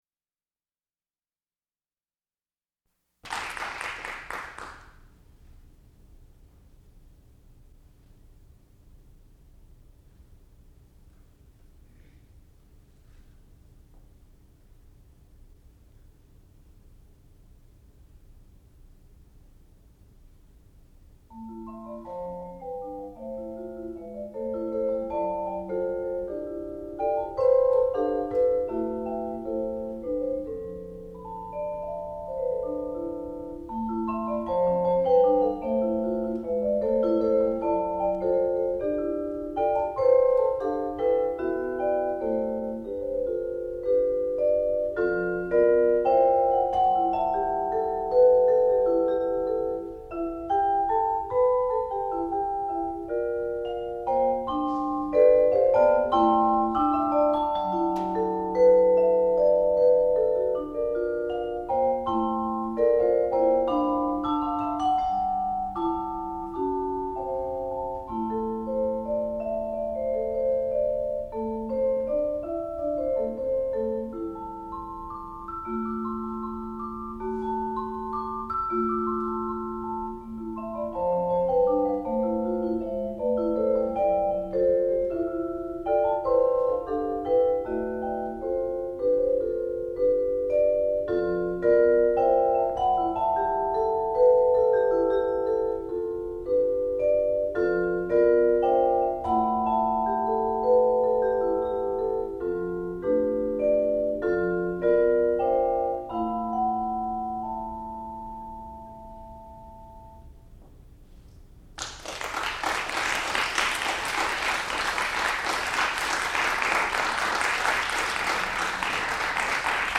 sound recording-musical
classical music
Junior Recital
percussion